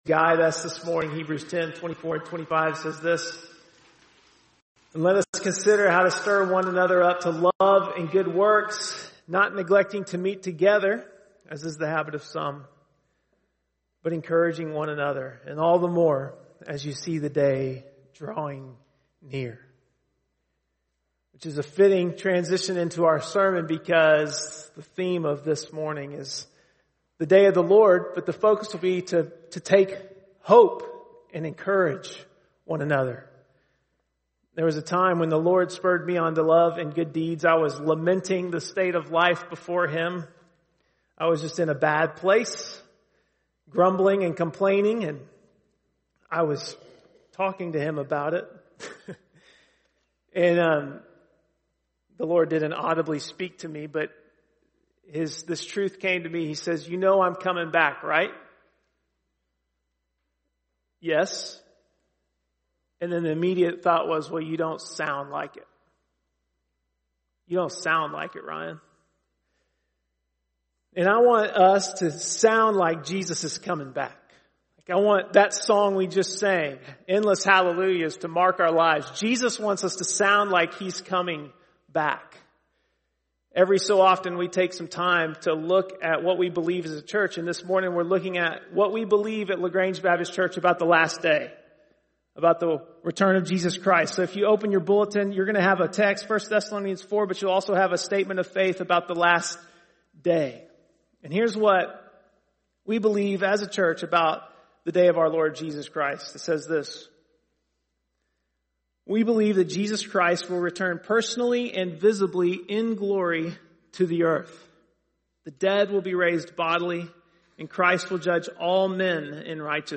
Various sermons on the Statement of Faith of LaGrange Baptist Church.
10.24-sermon.mp3